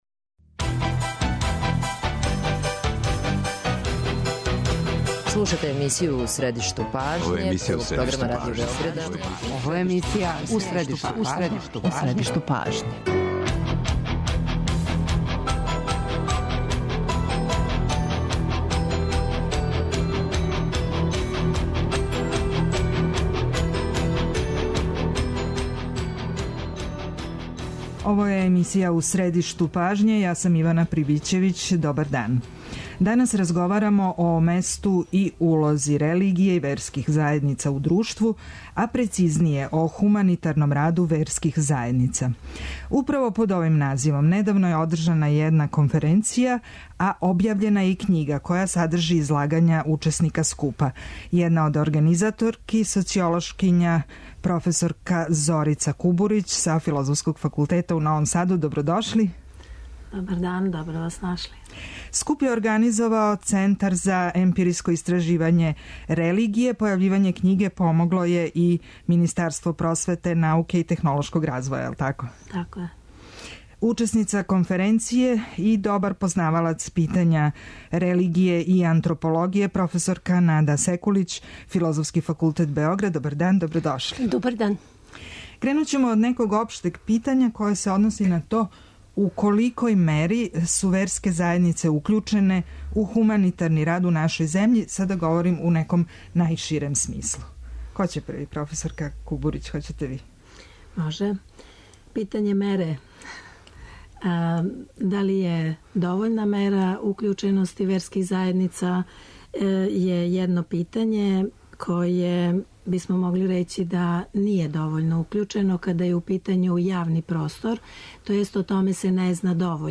доноси интервју са нашим најбољим аналитичарима и коментаторима